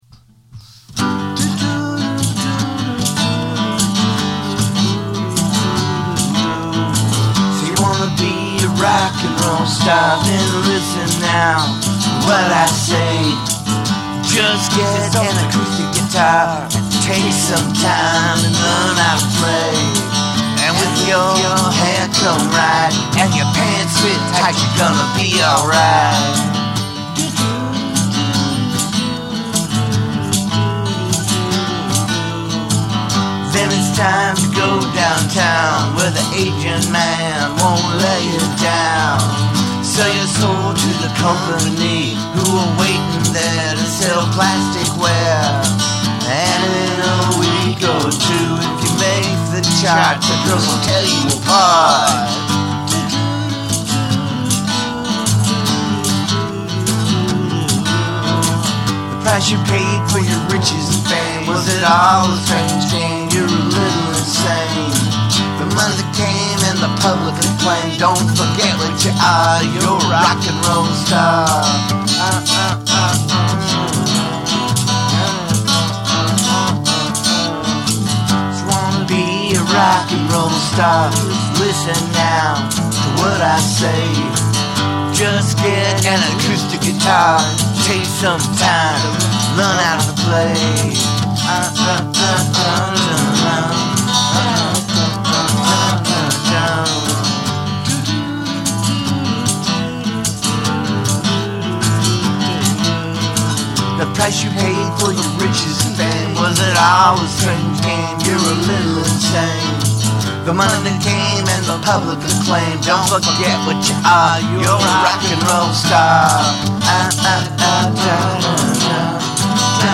Just get an acoustic guitar and learn how to PLAY!